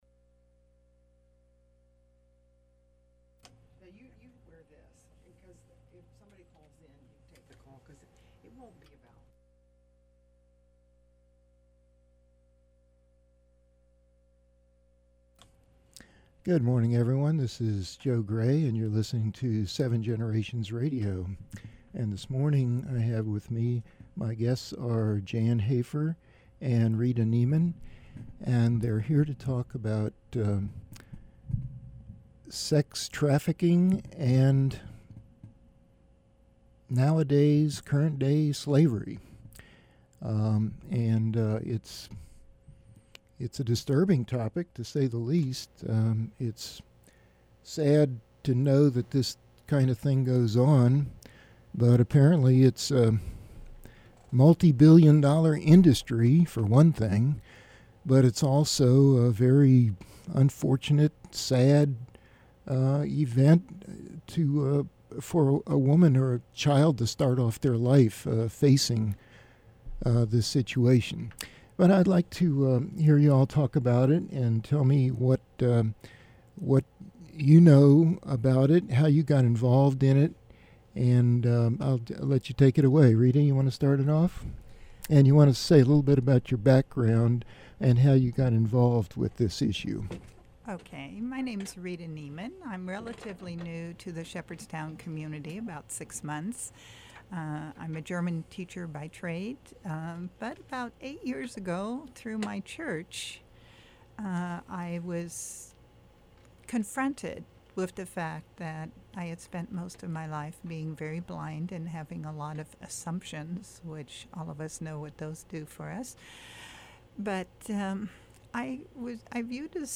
Broadcast on WSHC Shepherdstown, WV from the campus of Shepherd University on April 2, 2016.